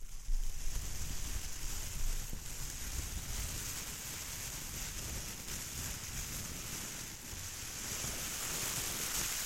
自然 " 雨和雷鸣
描述：雨和一些雷声的原始立体声录音。
标签： 毛毛雨 风暴 闪电 自然 天沟
声道立体声